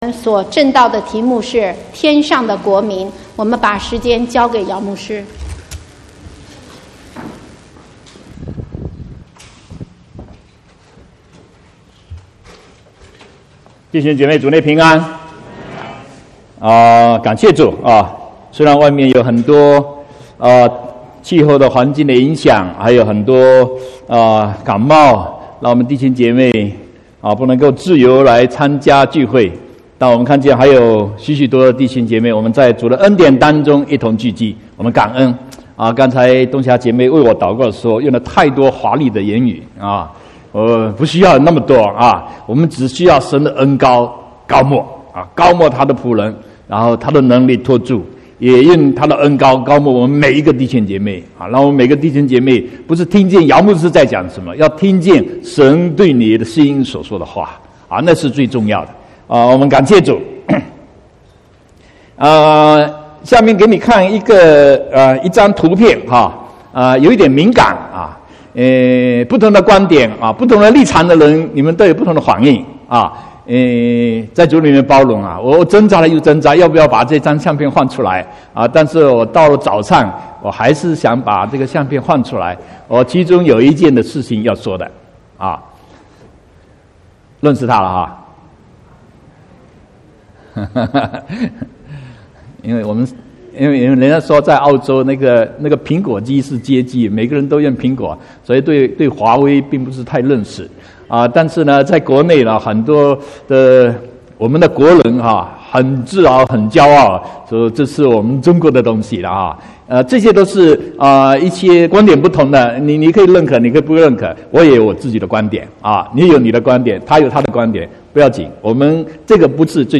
16/12/2018 國語堂講道